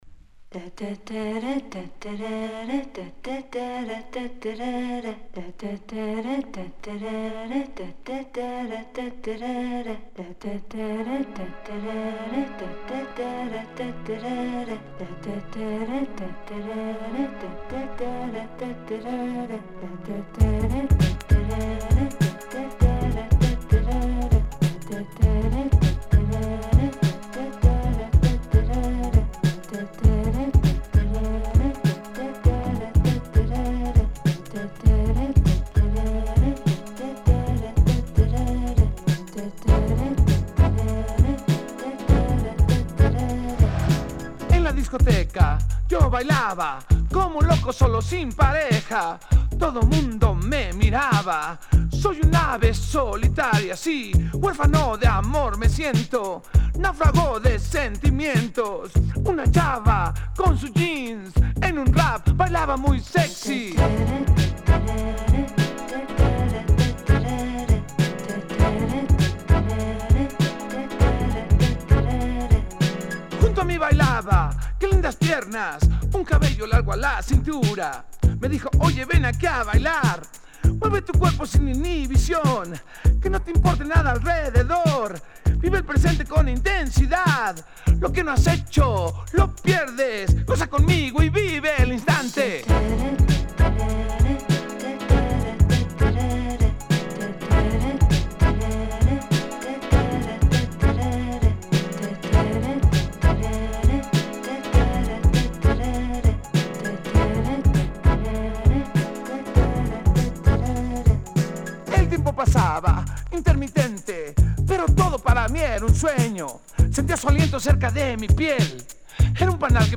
Published February 7, 2010 Electro Latino , Queso Comments